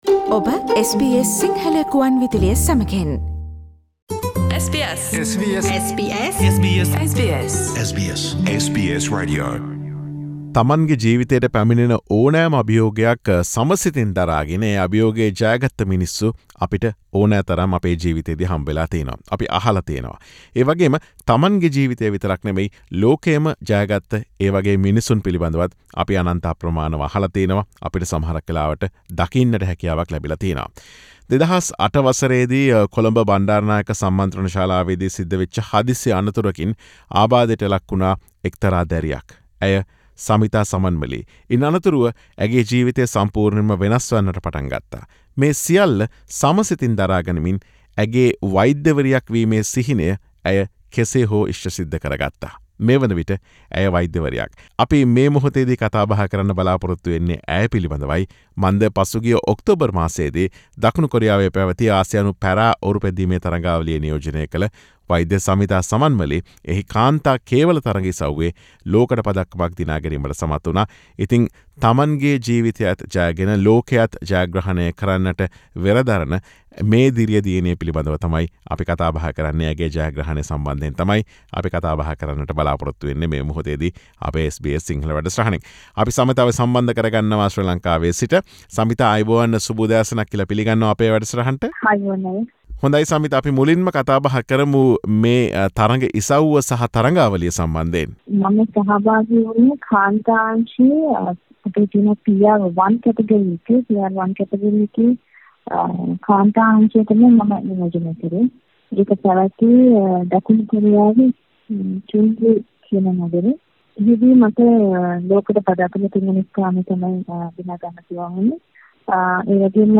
ඇගේ එම ජයග්‍රහණය පිළිබඳ SBS සිංහල ගුවන් විදුලිය සමග සිදුකළ සාකච්ඡාව.